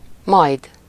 Ääntäminen
IPA: /dɑn/